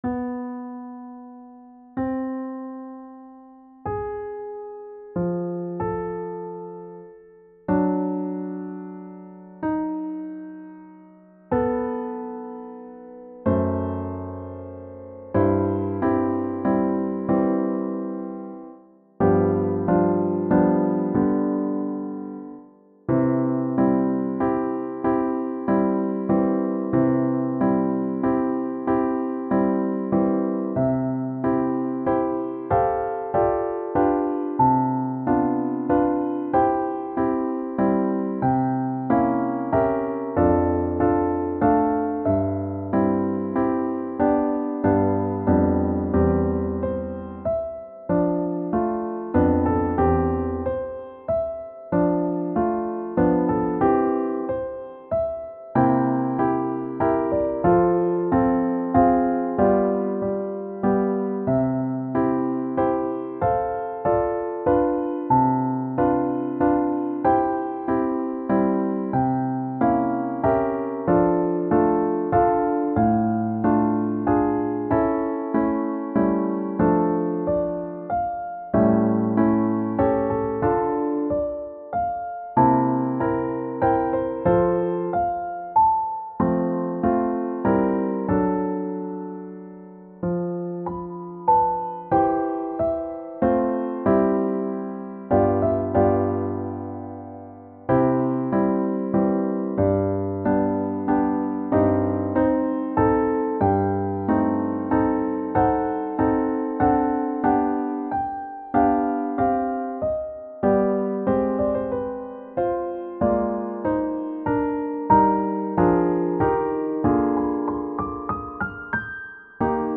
• Music Type: Organ
• Voicing: Organ